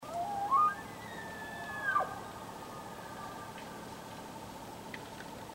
On this page you now have access to actual recordings of live elk in the wild.
The elk recordings are grouped into loose categories for ease of use, but some sound bytes have both cows and bulls in them, as well as other animals, like coyotes, woodpeckers, squirrels, etc. The recordings are high quality, so the sounds of the forest are in the background, like the sound of creeks flowing.
bugle_distant1.mp3